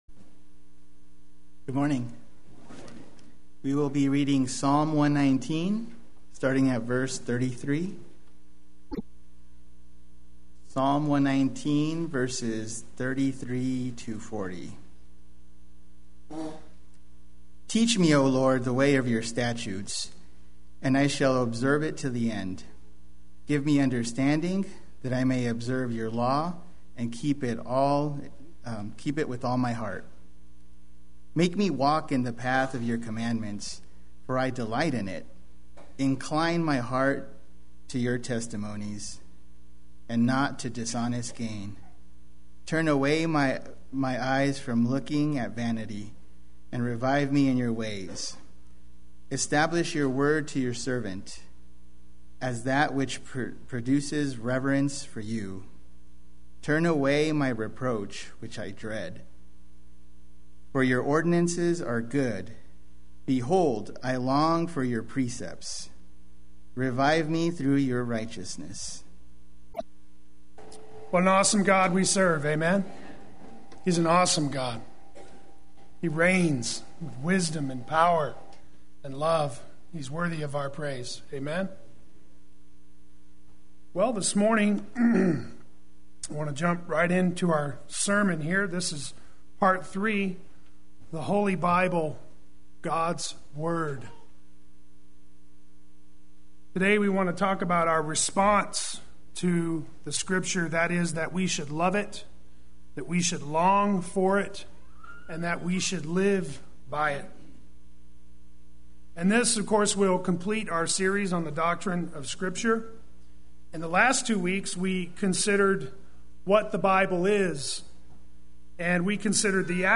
Play Sermon Get HCF Teaching Automatically.
Live by It Sunday Worship